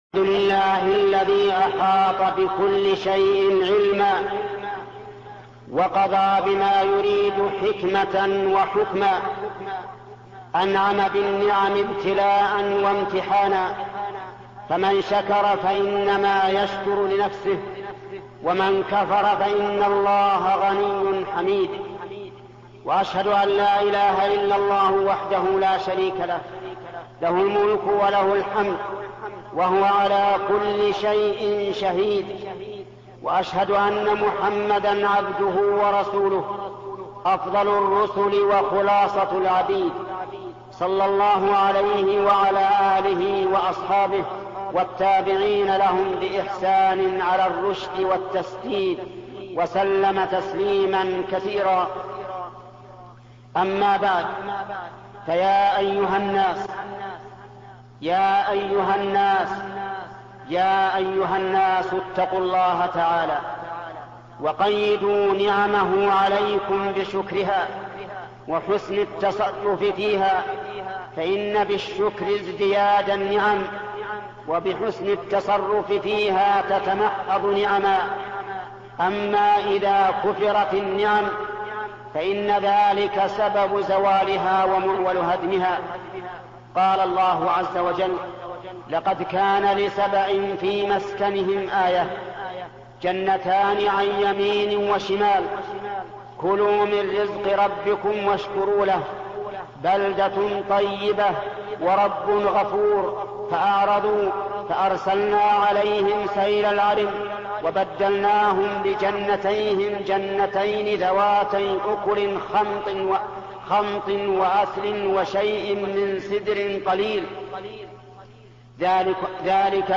الشيخ محمد بن صالح العثيمين خطب الجمعة